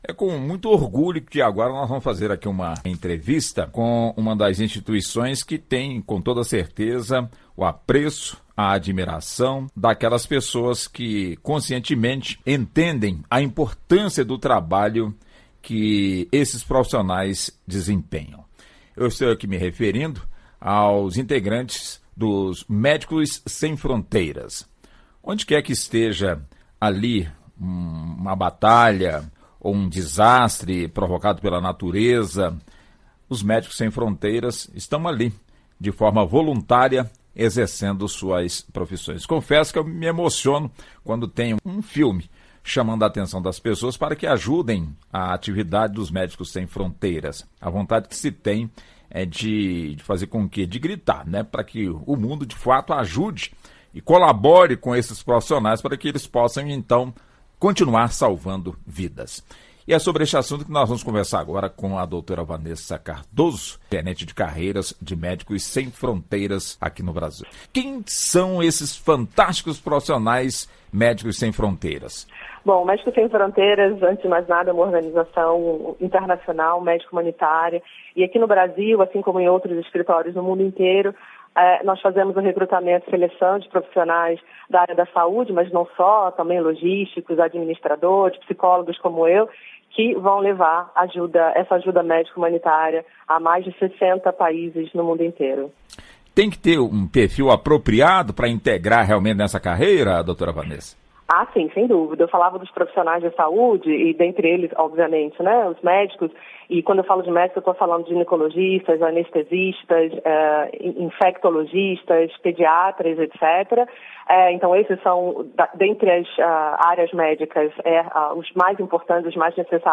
Entrevista: Conheça o trabalho dos Médicos Sem Fronteiras